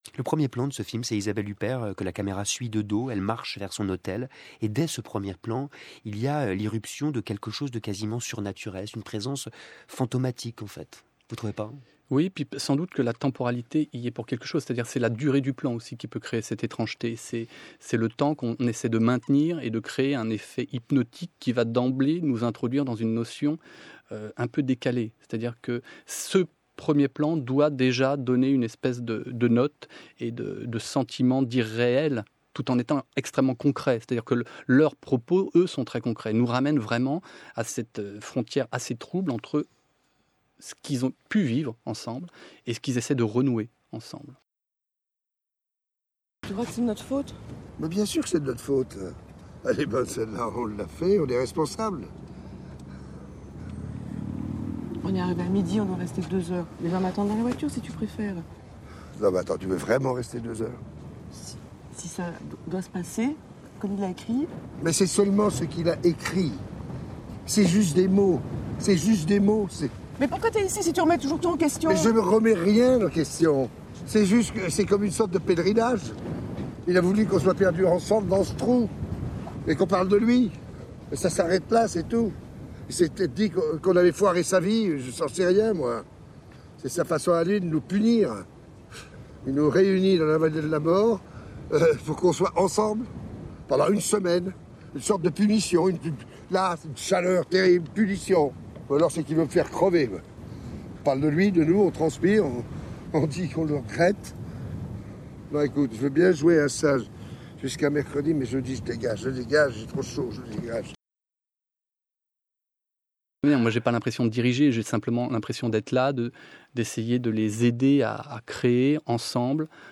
Voici un extrait d’une interview que j’ai beaucoup aimée.